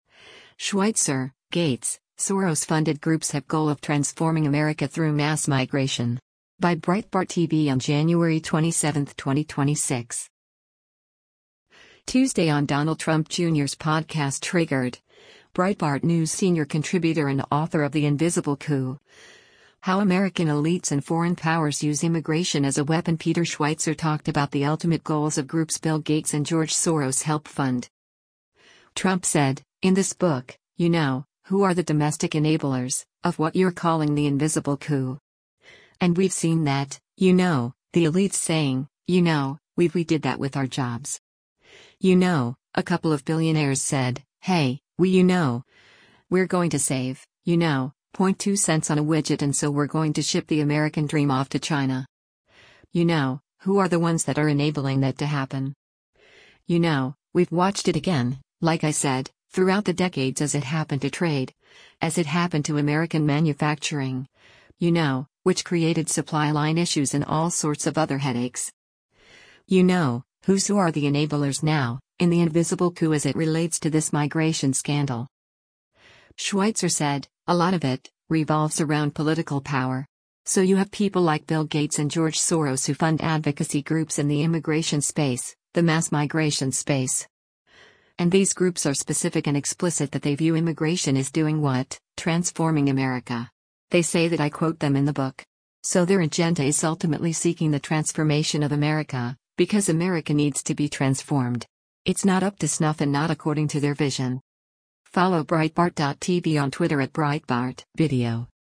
Tuesday on Donald Trump Jr’s podcast “Triggered,” Breitbart News senior contributor and author of The Invisible Coup: How American Elites and Foreign Powers Use Immigration as a Weapon Peter Schweizer talked about the ultimate goals of groups Bill Gates and George Soros help fund.